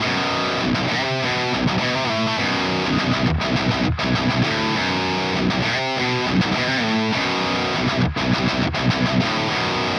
Здесь пивик 6505 уже с грелкой, в одном случае это максон 808, в другом precision drive, настройки ампа при этом одинаковые. В качестве нагрузки - ректокаб 4х12.
С линейными импульсами примерно так: